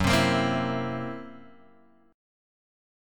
F#7b5 chord {2 3 x 3 1 0} chord